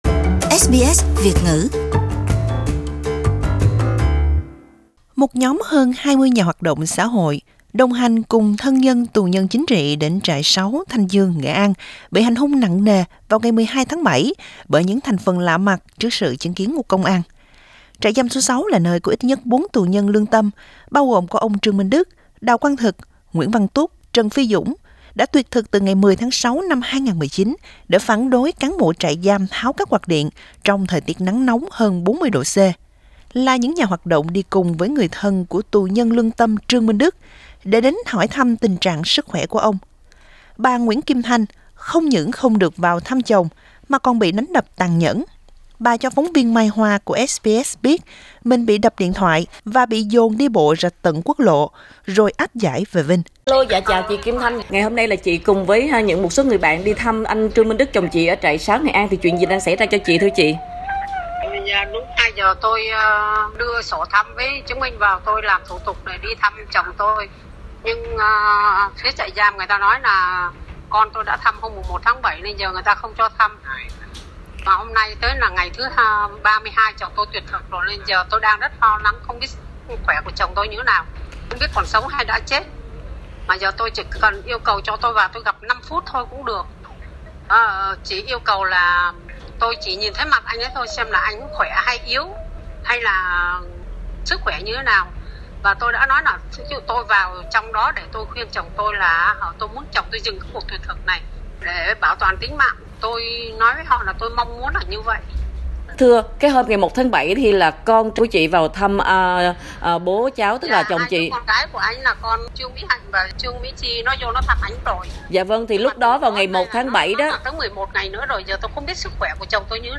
Mời quý vị bấm vào audio để nghe nguyên văn phần phỏng vấn.